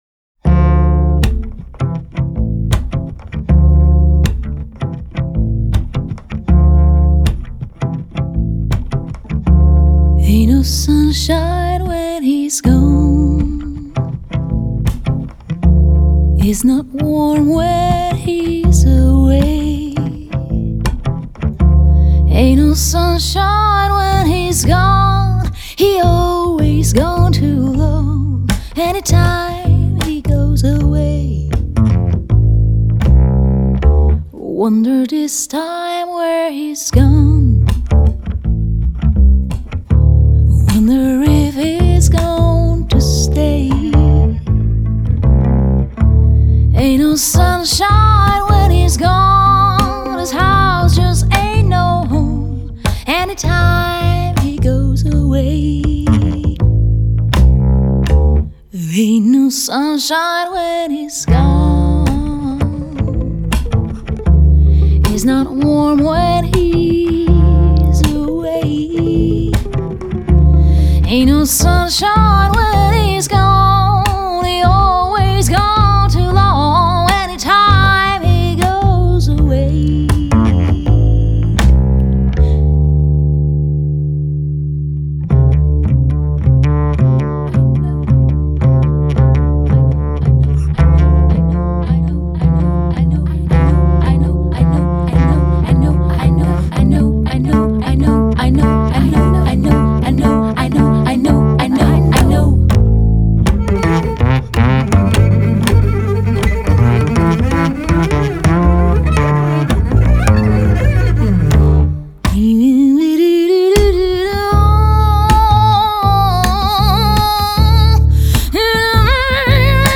Жанр: Jazz/Pop.